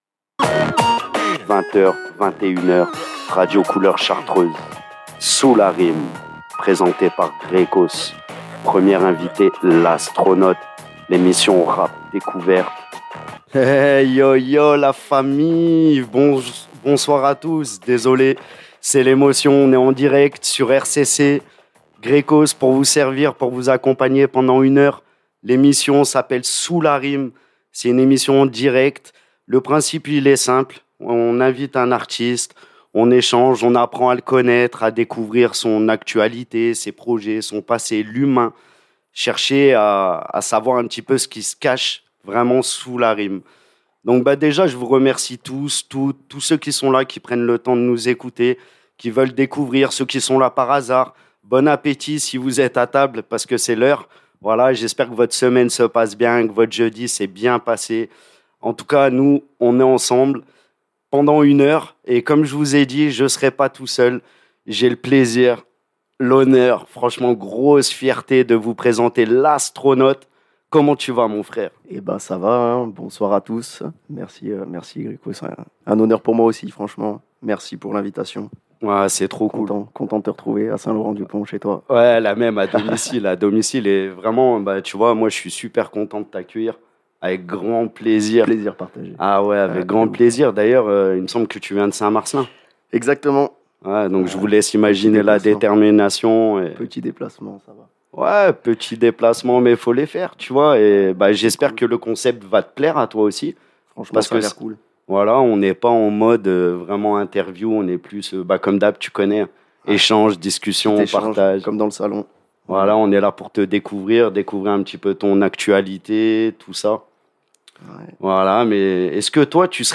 Nouvelle émission mensuelle en direct à 20H le jeudi !